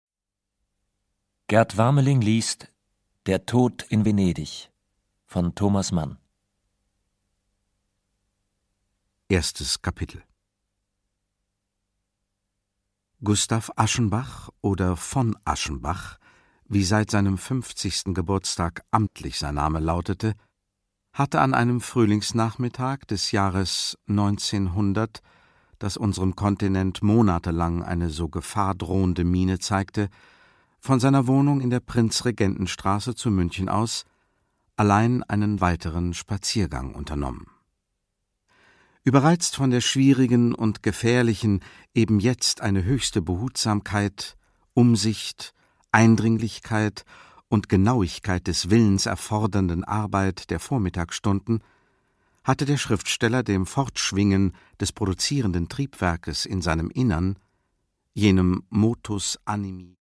Thomas Mann: Der Tod in Venedig (Ungekürzte Lesung)
Produkttyp: Hörbuch-Download
Gelesen von: Gerd Wameling